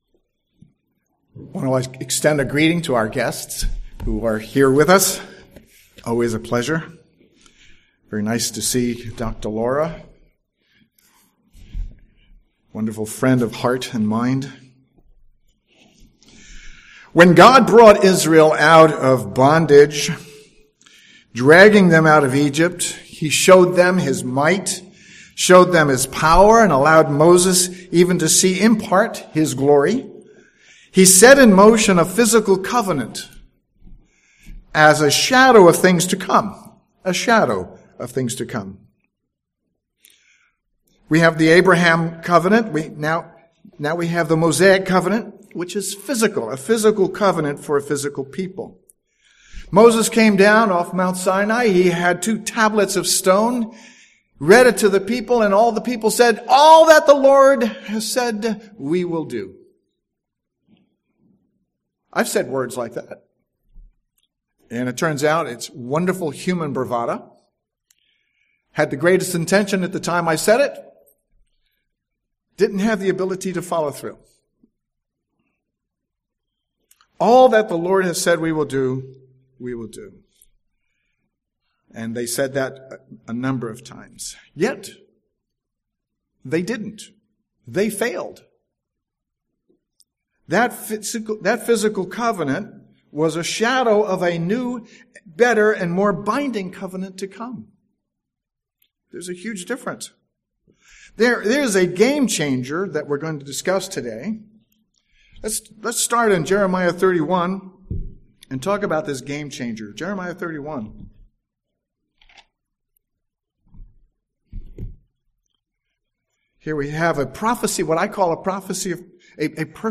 Listen to this sermon to learn what the New Covenant baptism is all about.